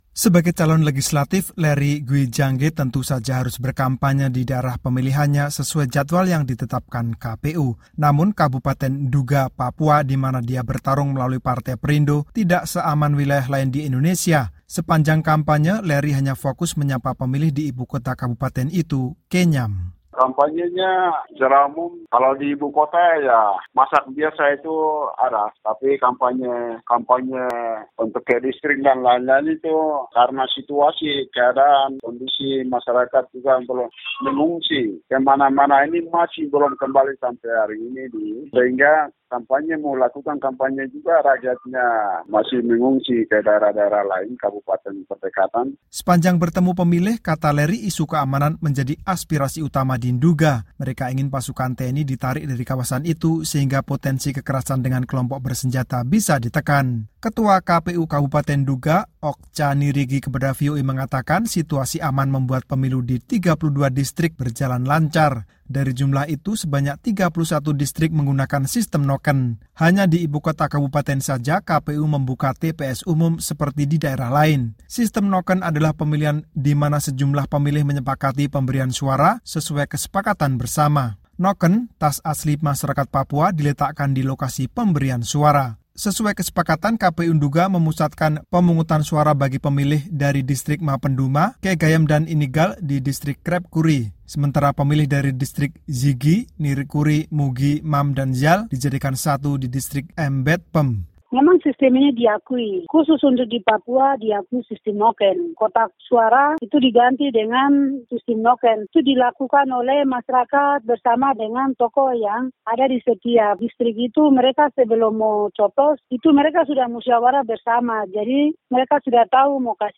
Namun, Pemilu 17 April kemarin berjalan lancar, meski dengan berbagai penyesuaian. Berikut laporan selengkapnya.